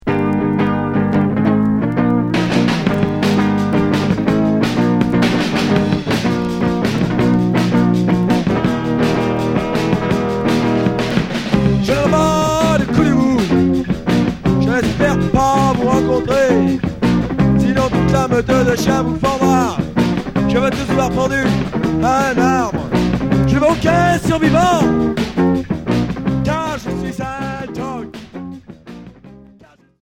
Punk rock